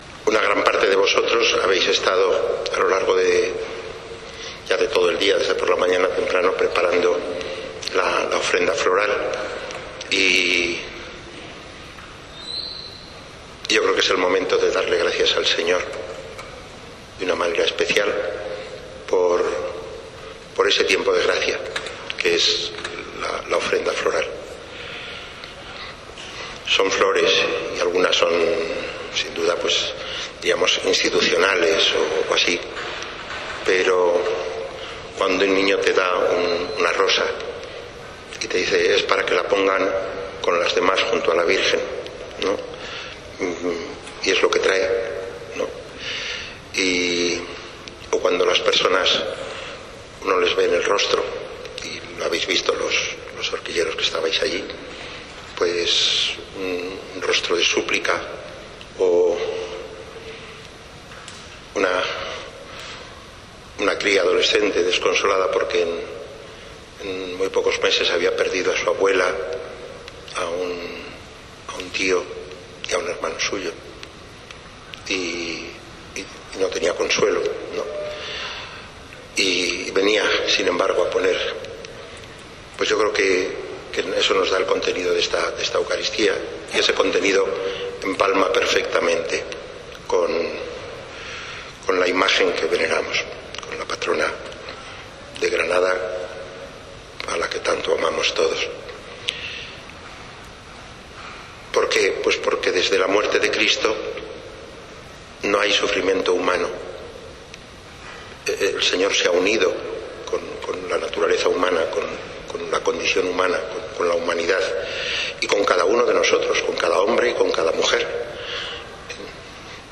Homilía de Mons. Javier Martínez en la Eucaristía con la que consagró la iglesia dedicada a Santa Josefina Bakhita, en Playa Granada (Motril). Mons. Martínez explica qué es consagración, dedicación y parroquia, para señalar la importancia del lugar que a partir de ahora acoge a los fieles.